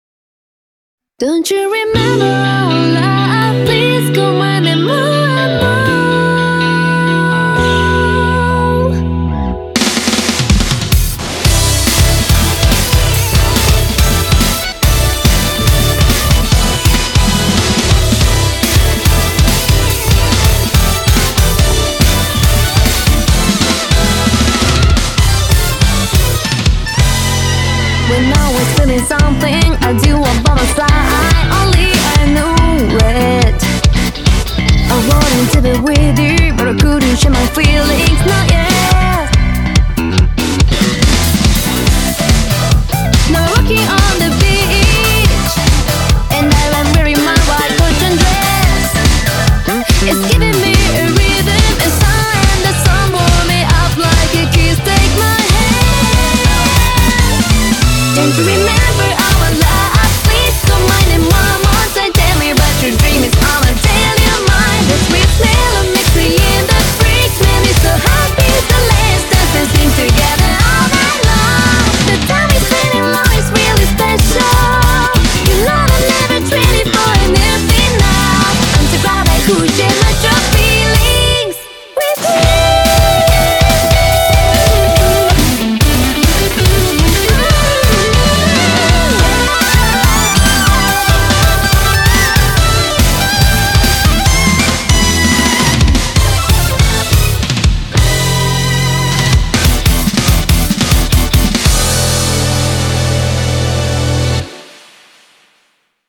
BPM123-142
Audio QualityPerfect (Low Quality)